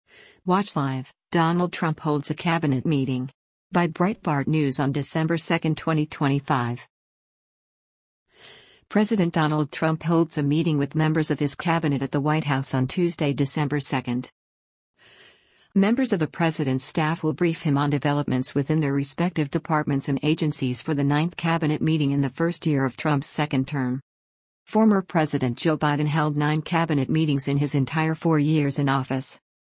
President Donald Trump holds a meeting with members of his cabinet at the White House on Tuesday, December 2.